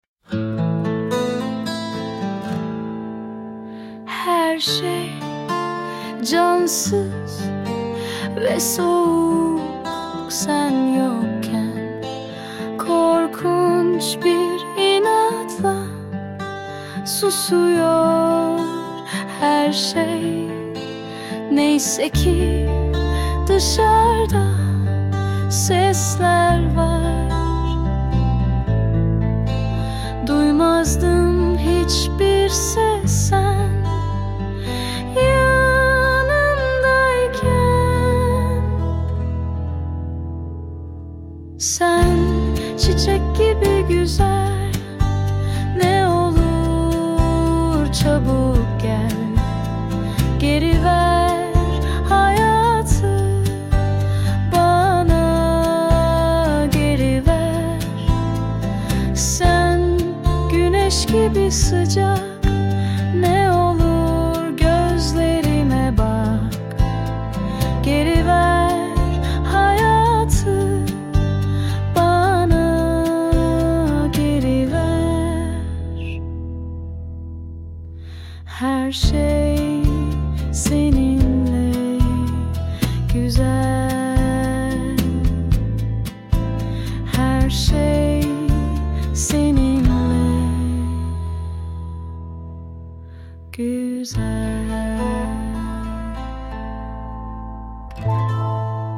Tür : Akdeniz, Pop, Slow Rock